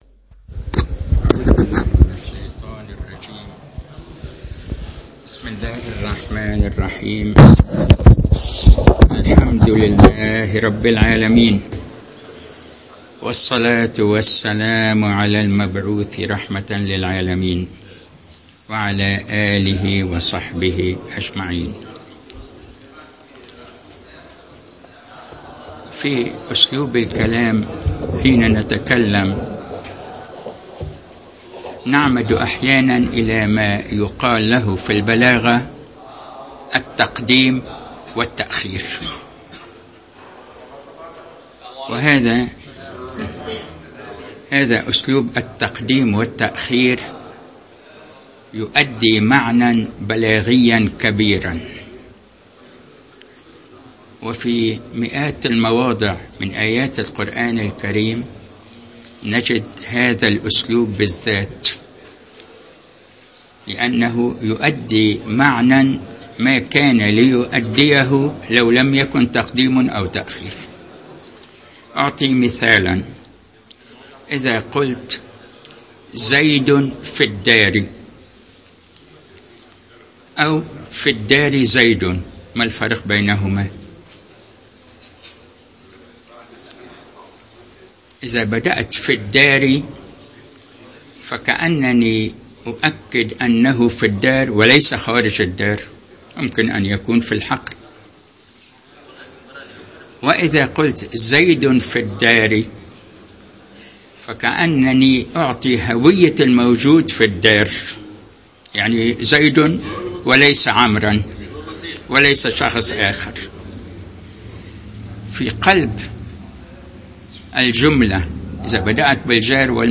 المكان : المسجد البحري